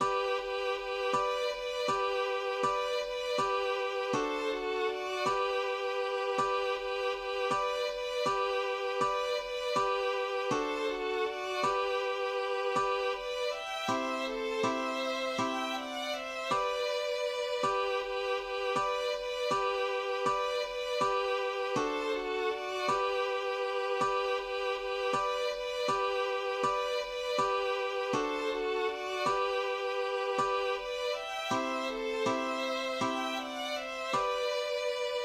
Spottgesang aus dem Hartsteenschen